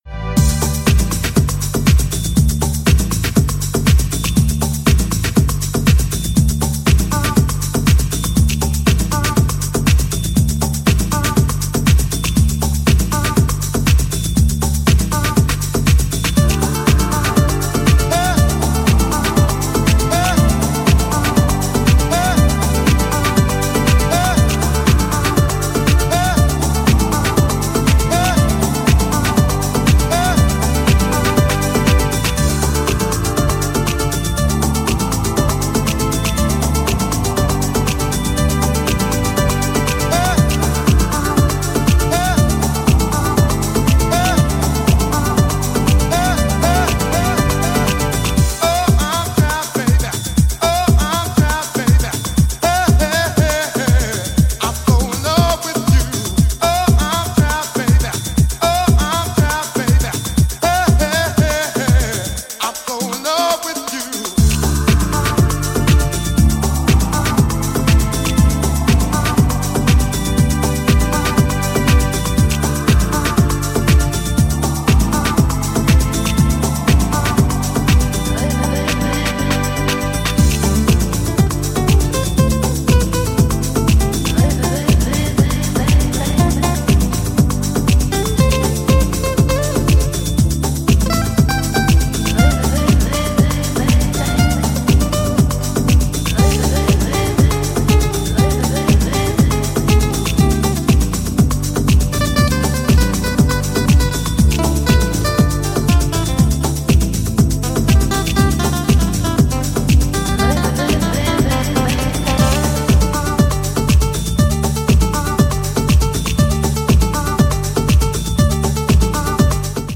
スパニッシュ・ギターがフィーチャーされている